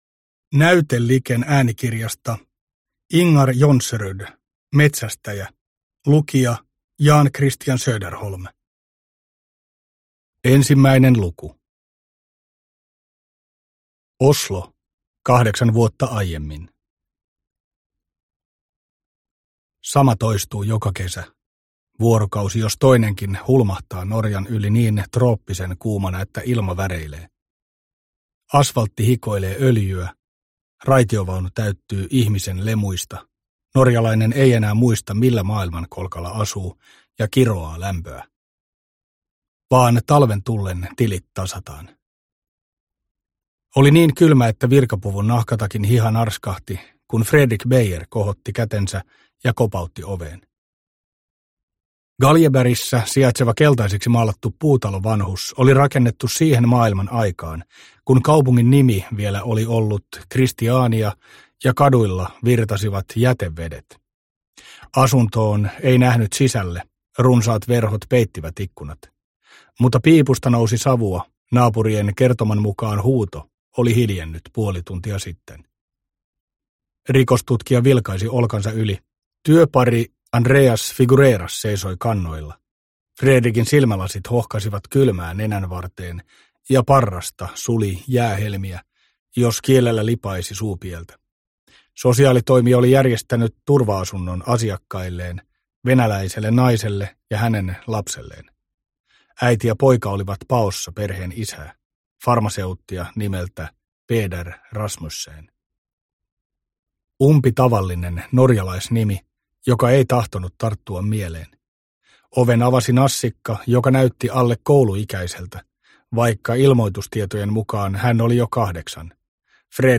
Metsästäjä – Ljudbok – Laddas ner